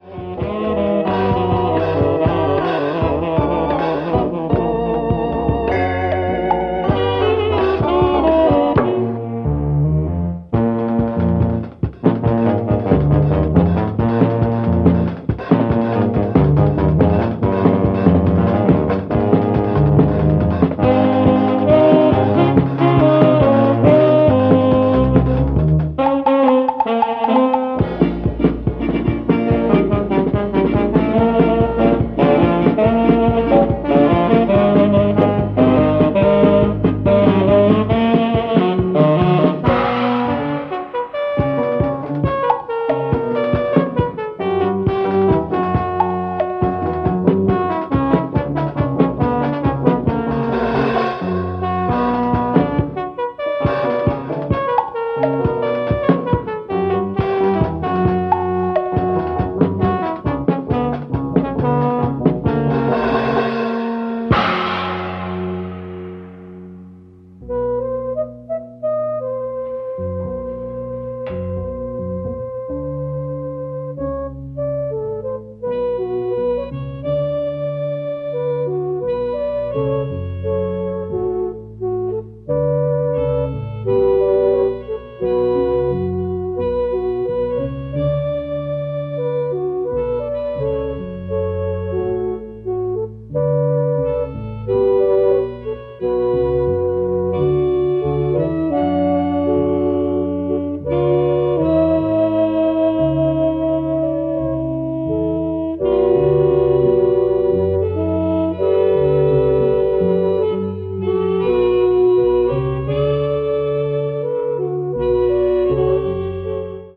keyboards
sax
drums
quirky and surreal in the French way
instinctive and dance-loving exotica